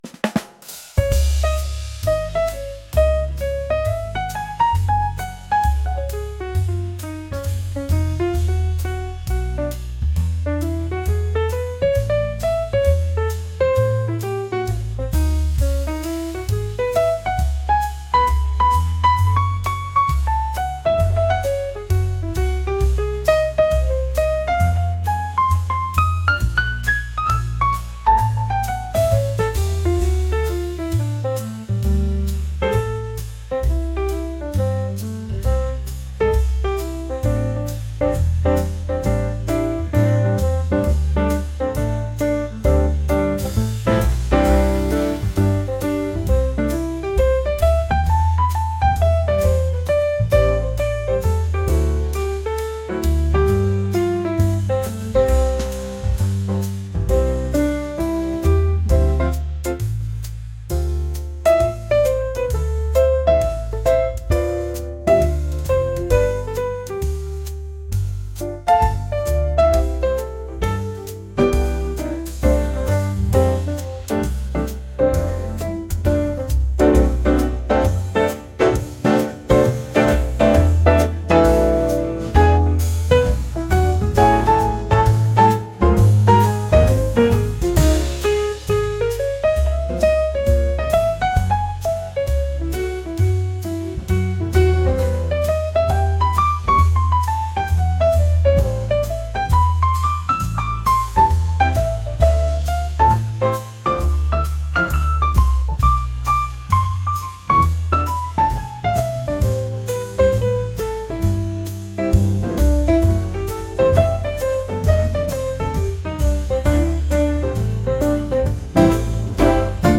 jazz | lounge | soul & rnb